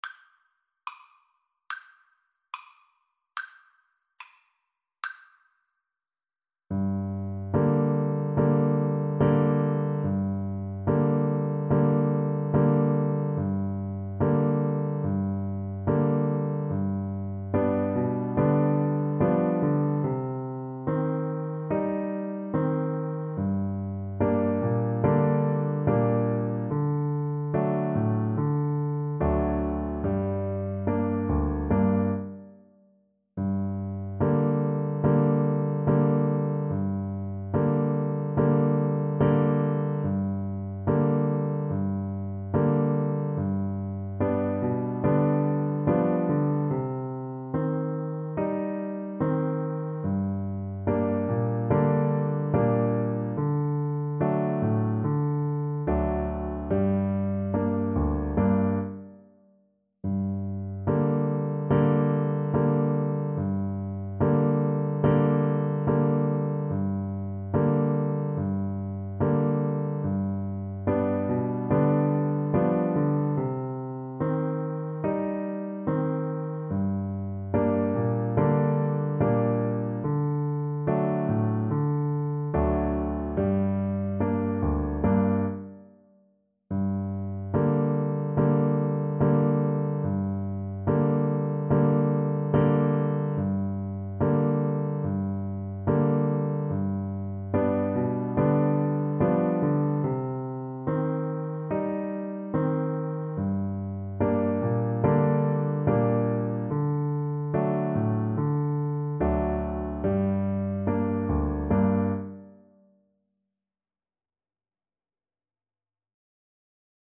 Free Sheet music for Piano Four Hands (Piano Duet)
Andante = c. 72
2/4 (View more 2/4 Music)